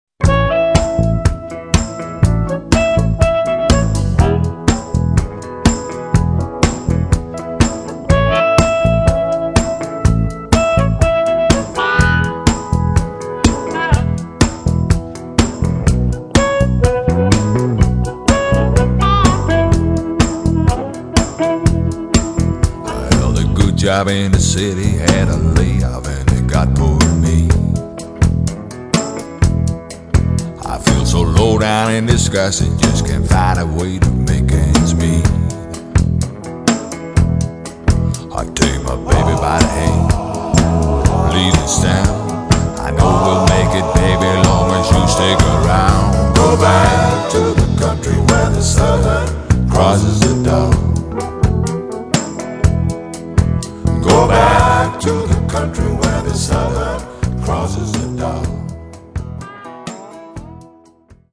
Guitar, Vocals
Keyboards
Bass
Drums
Background Vocals